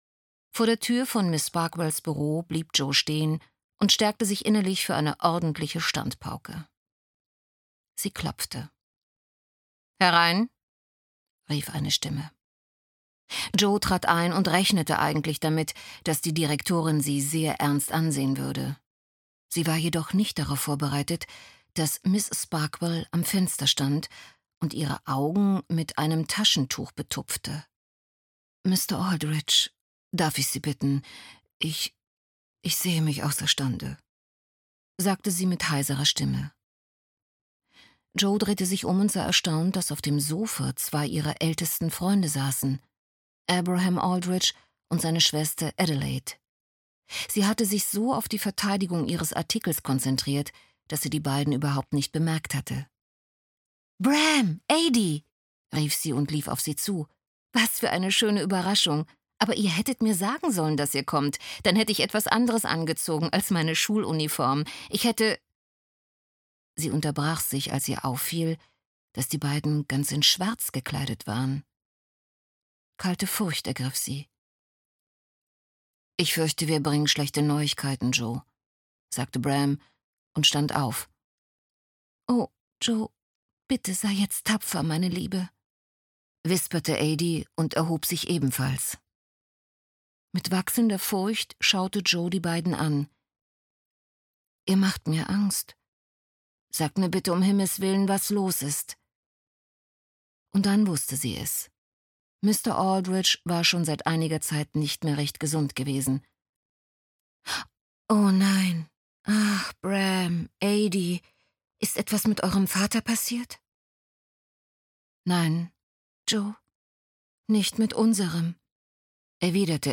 Straße der Schatten - Jennifer Donnelly - Hörbuch